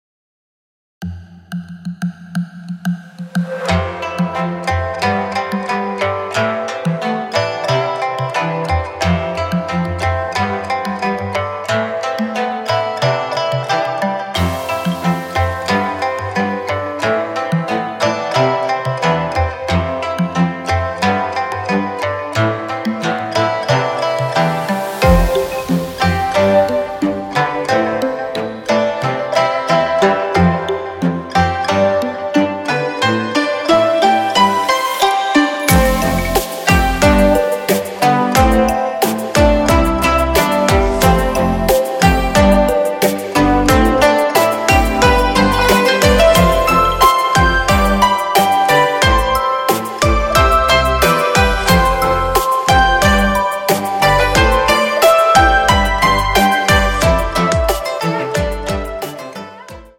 流派: 古典乐
而当琵琶与扬琴碰撞在一起时，这盘玉珠又焕发出了别样的光彩。
轻拢慢捻抹复挑，在弹指拨动间，仿若一位鲜活美丽的佳人舞动，定睛细瞧，盘中起舞的是琵琶、扬琴与大提琴三颗明珠。
录音棚：卜音工作室（原Indra Audio Studio）